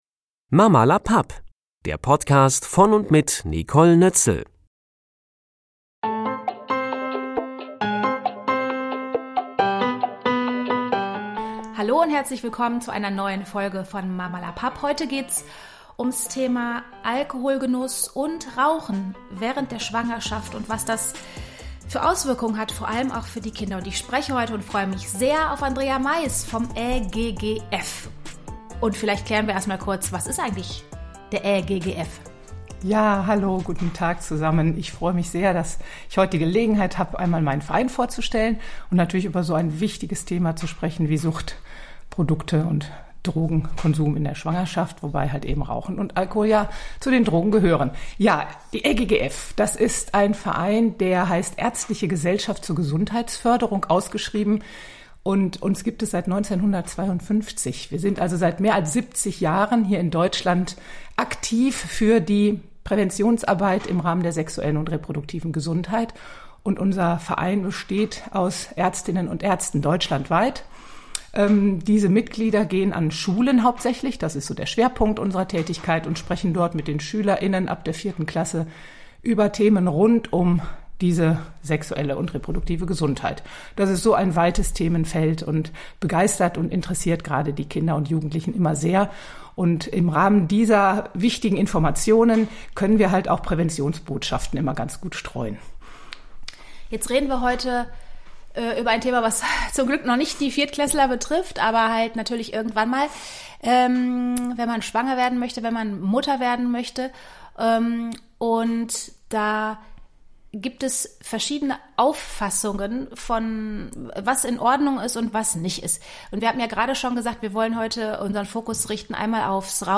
Ich spreche in dieser Podcastfolge mit Ärztin